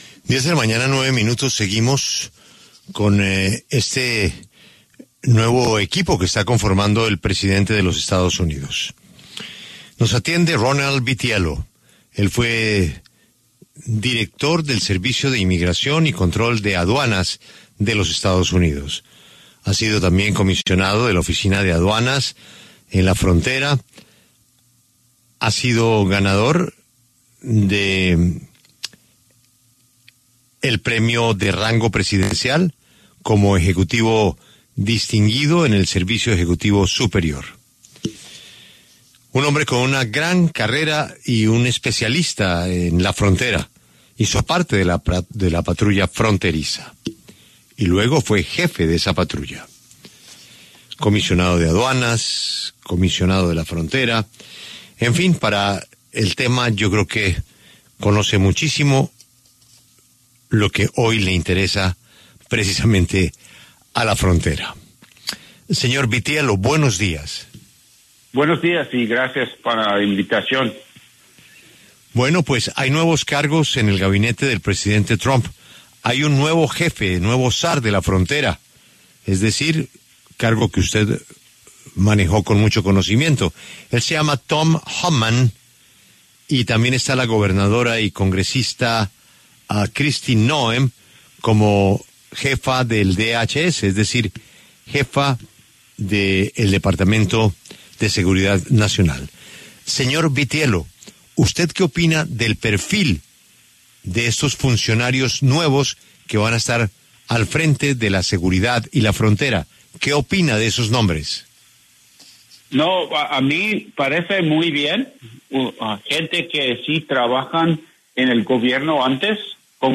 Ronald Vitiello, director interino del Servicio de Inmigración y Control de Aduanas de los EE.UU. en 2019 habló en La W sobre las designaciones para el Gabinete Trump y detalló sus planes al llegar a la Casa Blanca.